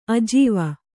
♪ ajīva